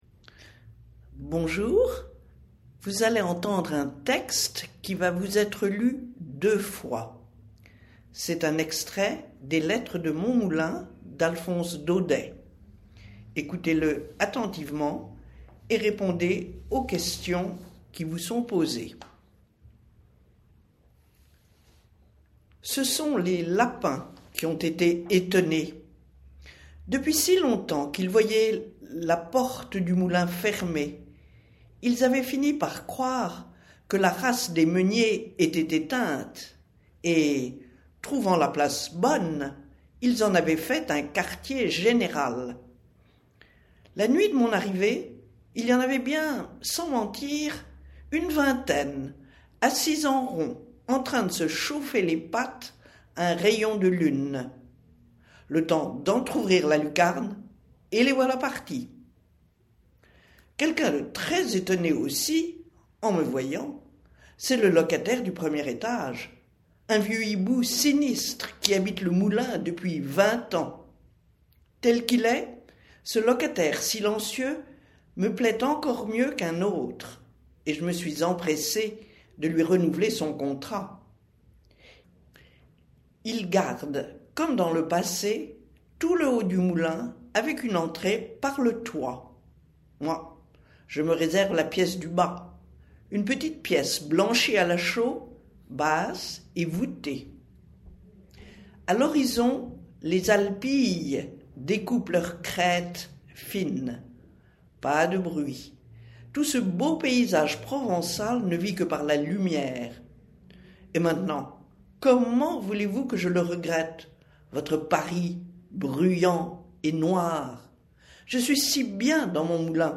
Premier exercice du sujet, texte à lire deux fois